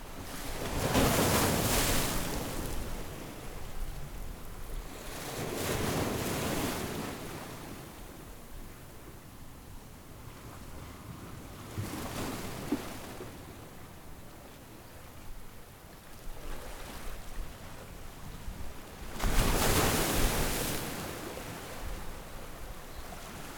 crashing-waves-into-rocks-1.wav